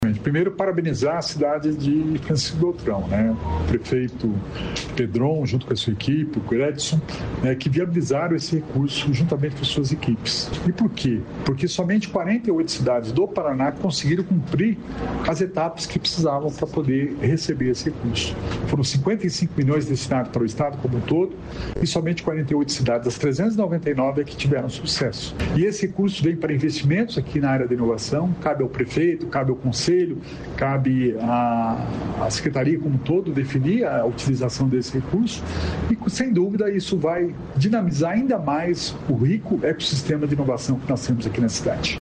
Sonora do secretário da Inovação e Inteligência Artificial, Alex Canziani, sobre investimentos nas cidades do Sudoeste